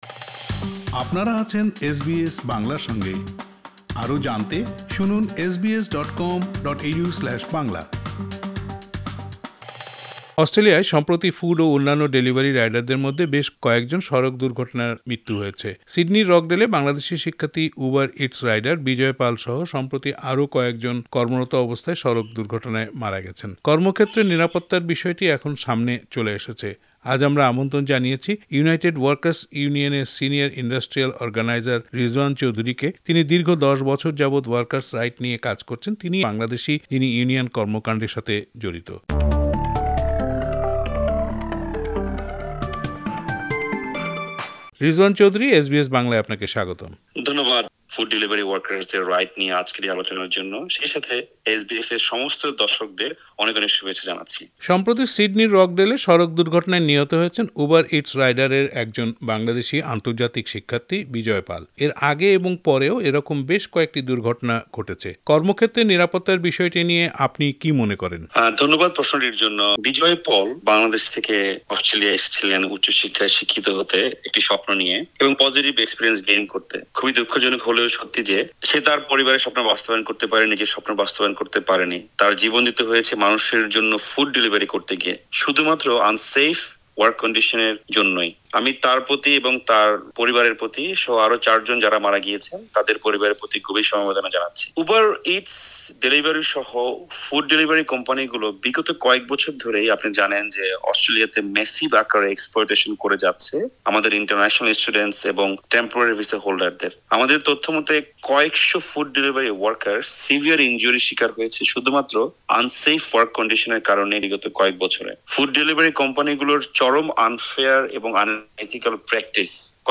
এ প্রসঙ্গে এসবিএস বাংলার সাথে কথা বলেছেন…